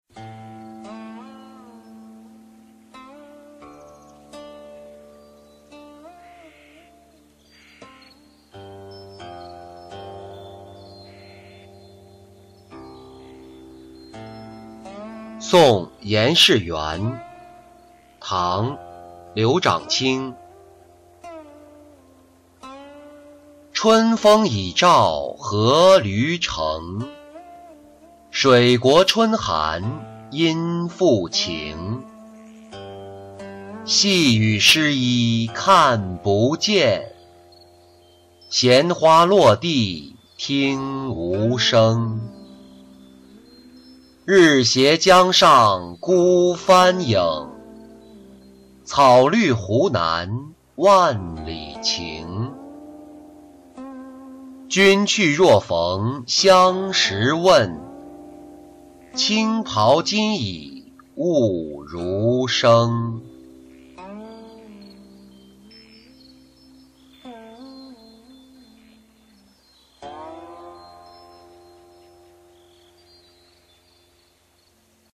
别严士元-音频朗读